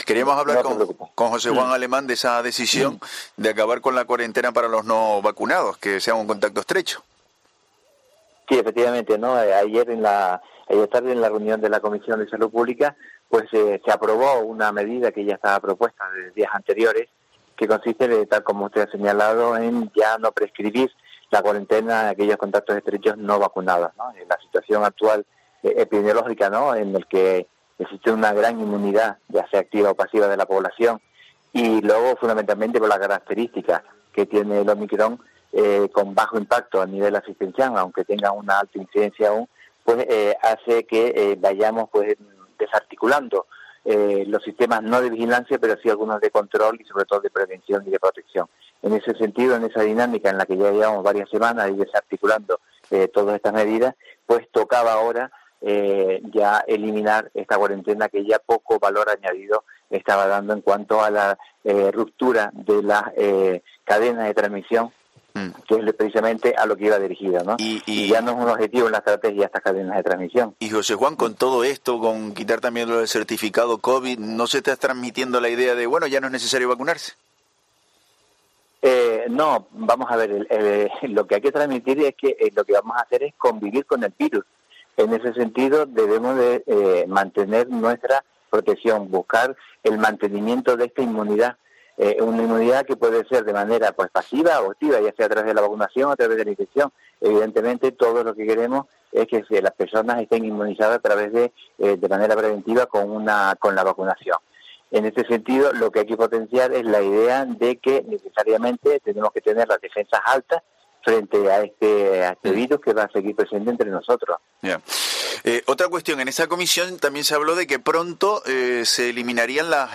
Entrevista a José Juan Alemán, director general de Salud Pública (2/3/2022)